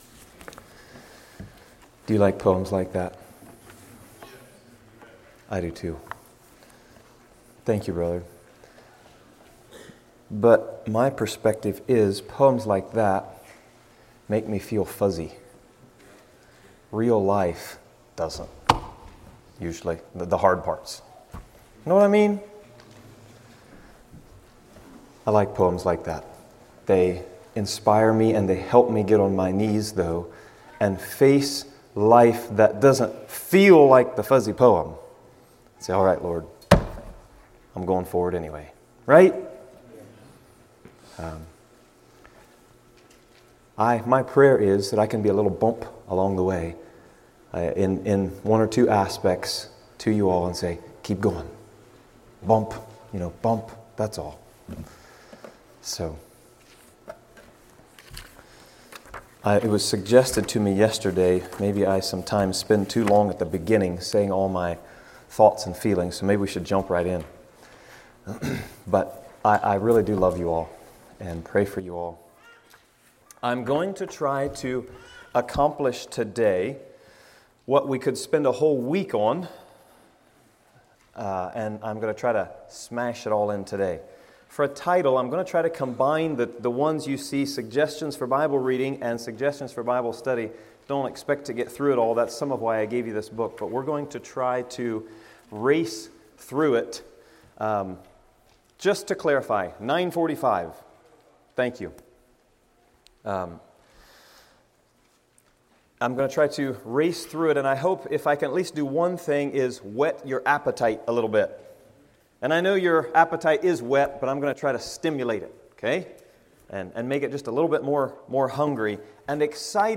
A message from the series "Bible Boot Camp 2025."